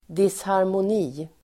Uttal: [disharmon'i:]
disharmoni.mp3